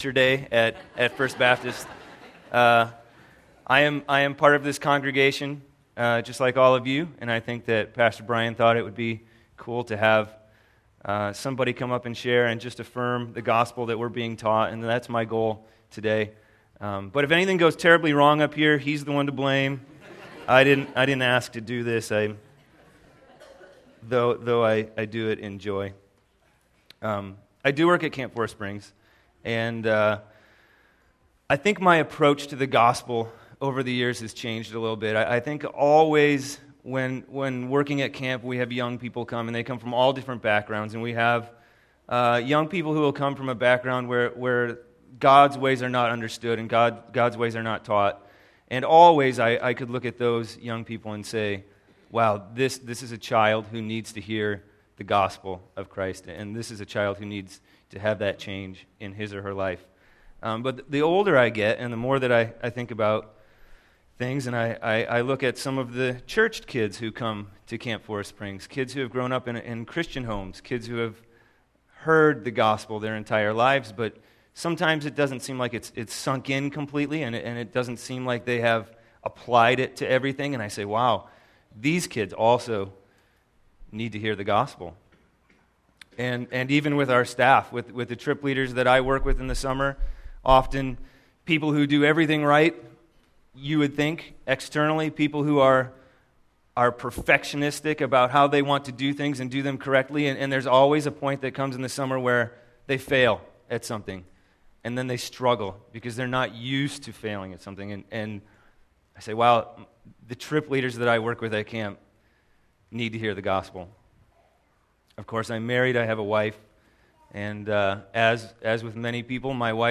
sermon71413.mp3